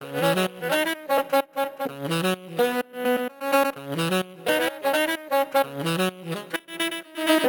Moustache_C#_128_FX.wav